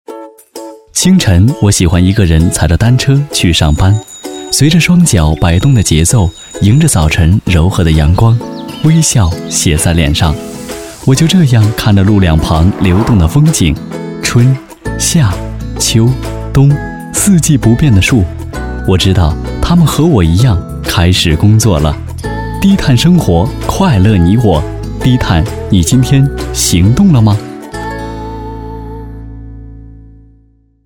广告 BTV 低碳出行公益广告 清新风格--声音作品--海滨声音艺术学院
CCTV 低碳公益广告 小清新版